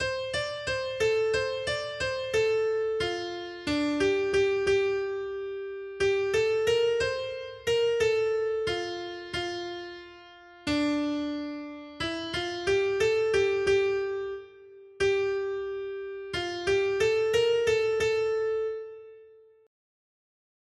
Noty Štítky, zpěvníky ol416.pdf responsoriální žalm Žaltář (Olejník) 416 Skrýt akordy R: Pochválen buď Hospodin, Bůh Izraele, neboť navštívil svůj lid. 1.